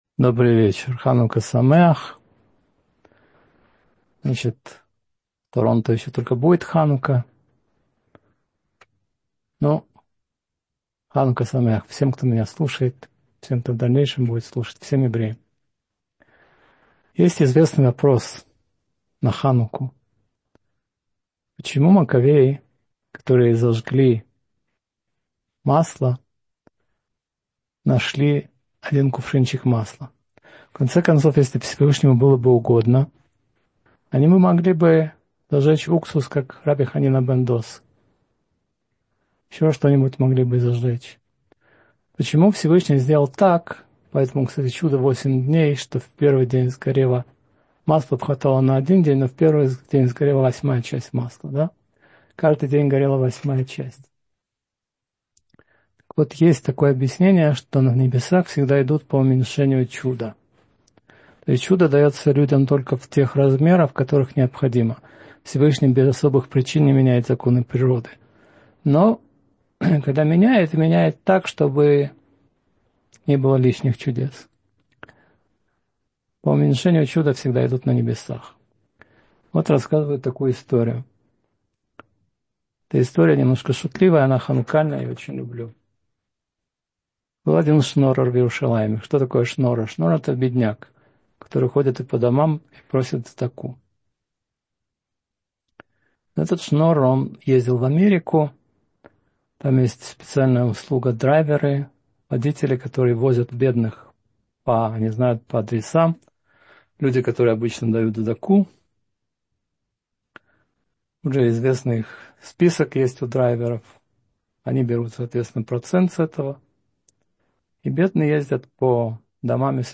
Микец. Влияние сновидений — слушать лекции раввинов онлайн | Еврейские аудиоуроки по теме «Недельная глава» на Толдот.ру